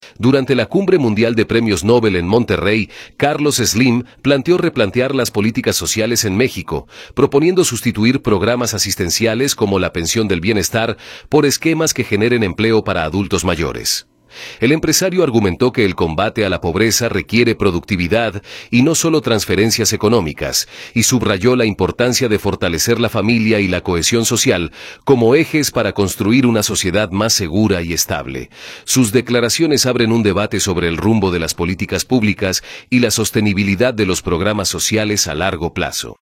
audio Durante la Cumbre Mundial de Premios Nobel en Monterrey, Carlos Slim planteó replantear las políticas sociales en México, proponiendo sustituir programas asistenciales como la Pensión del Bienestar por esquemas que generen empleo para adultos mayores. El empresario argumentó que el combate a la pobreza requiere productividad y no solo transferencias económicas, y subrayó la importancia de fortalecer la familia y la cohesión social como ejes para construir una sociedad más segura y estable.